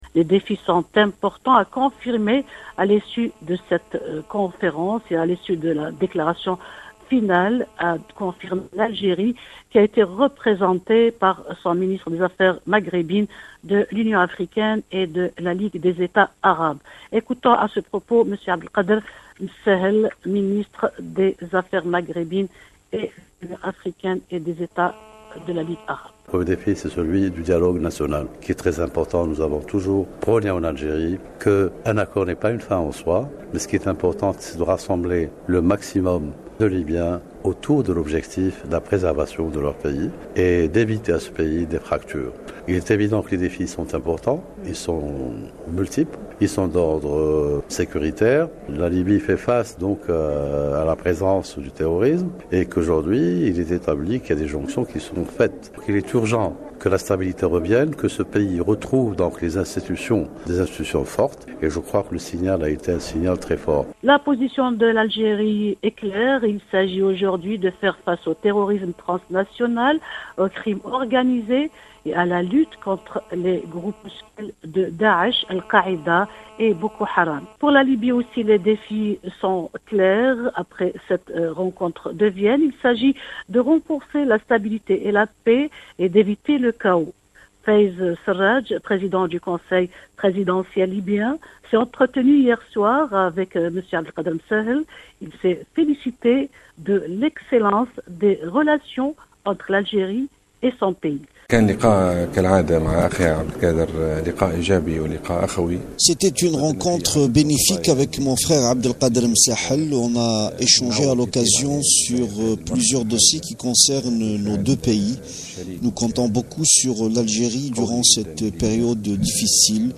Compte rendu